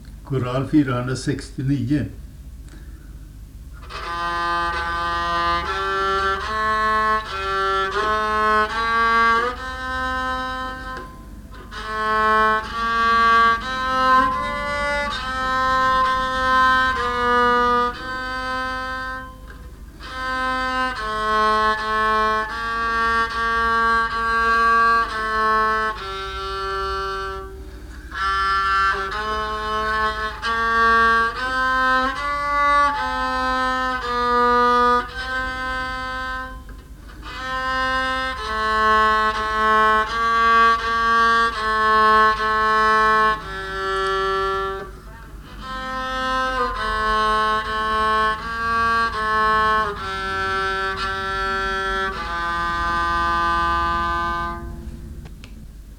tysk psalm
sann Gud och man på psalmodikon